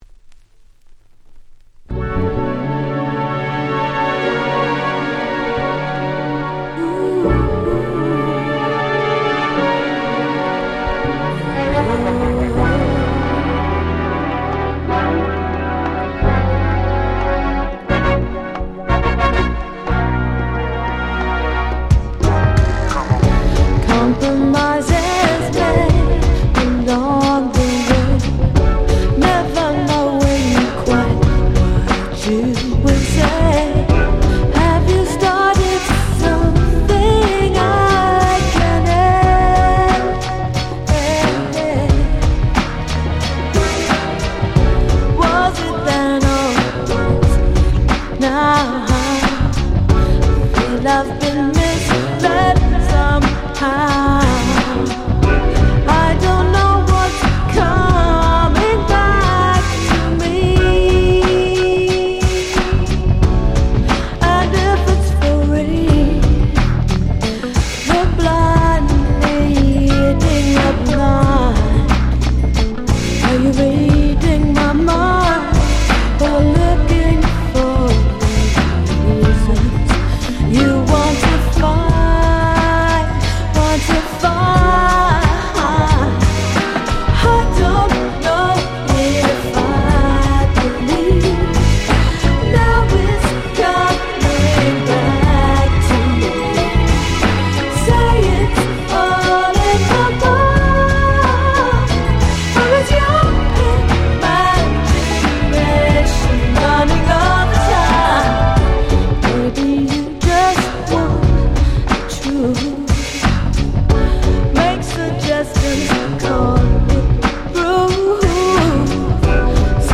最高のUK Soul Albumです。